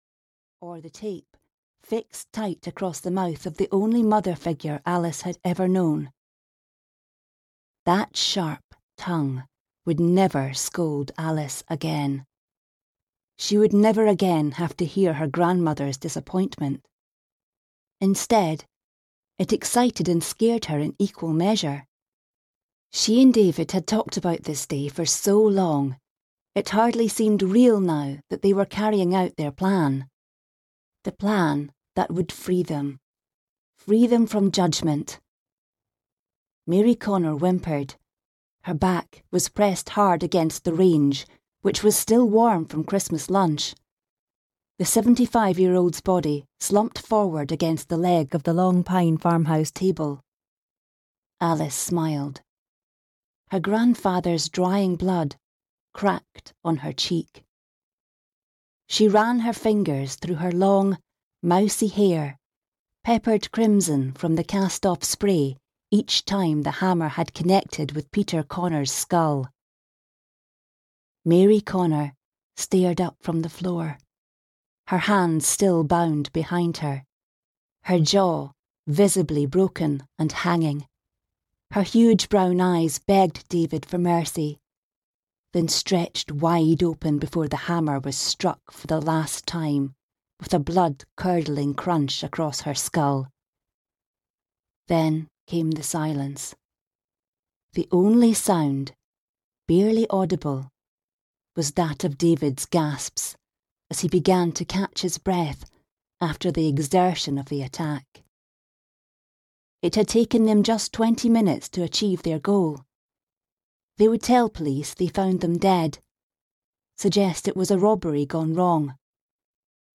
Her Secret Past (EN) audiokniha
Ukázka z knihy